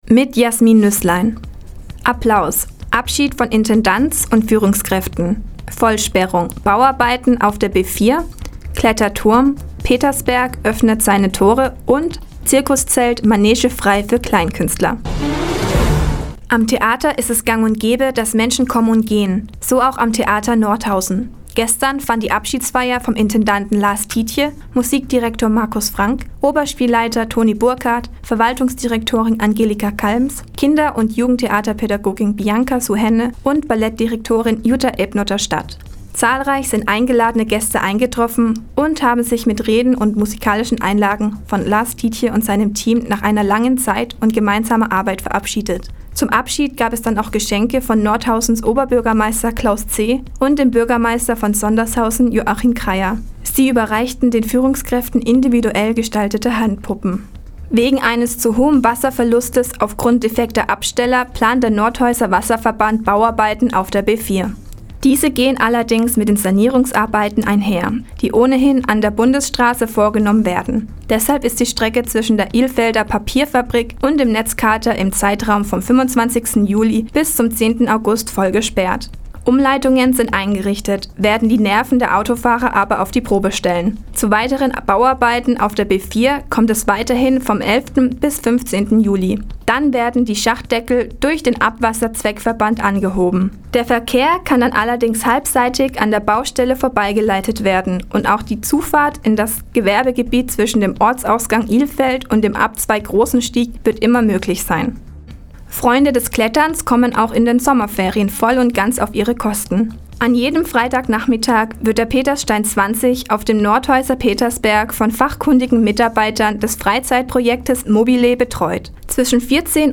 Mi, 17:00 Uhr 29.06.2016 Neues von Radio ENNO „Fazit des Tages“ Seit Jahren kooperieren die Nordthüringer Online-Zeitungen und das Nordhäuser Bürgerradio ENNO. Die tägliche Nachrichtensendung ist jetzt hier zu hören.